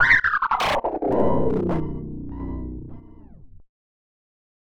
Glitch FX 03.wav